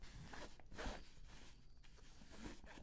标签： 脚步
声道立体声